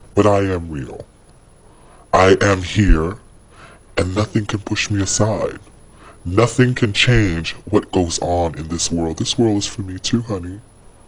The changing of pitch of this recording, starting with the same pattern of distortion, relates to the changes of voice that occur during the process of transition, as well as serves as a recognition of trans musical artists who edit their voice’s pitch, today mainly seen in the hyperpop genre.